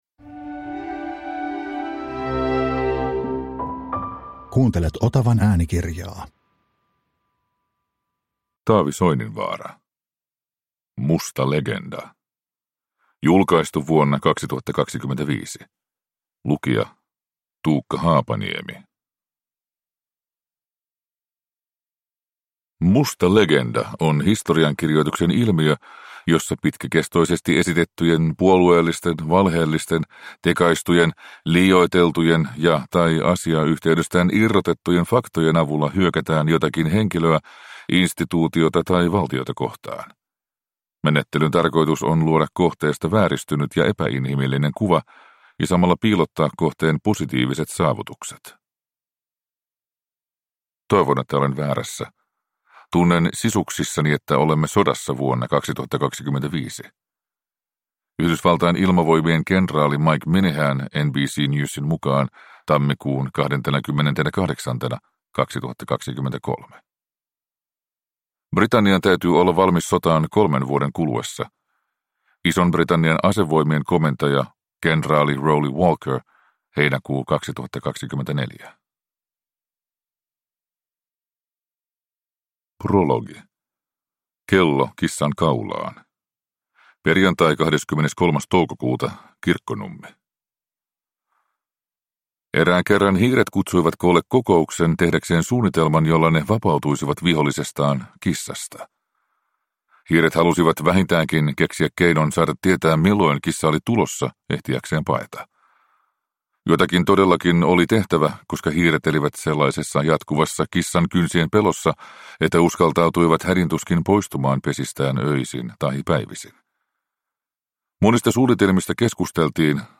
Musta legenda (ljudbok) av Taavi Soininvaara